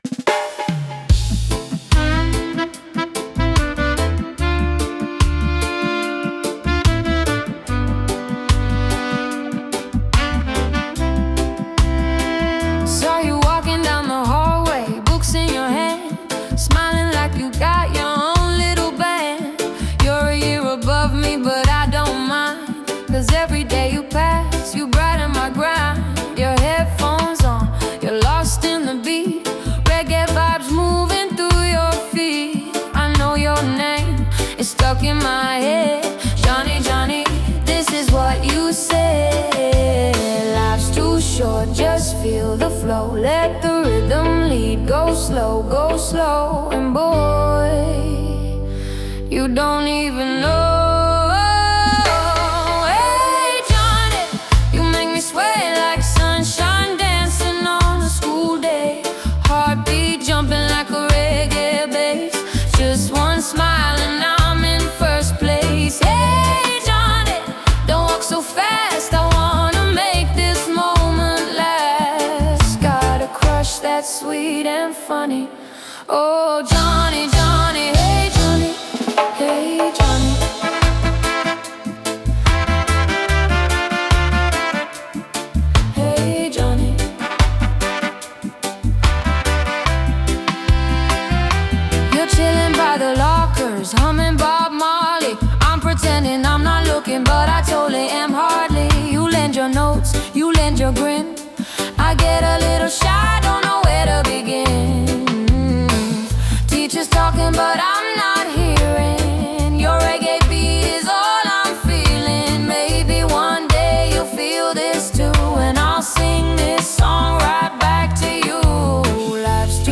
A fun, youthful song with a playful crush vibe.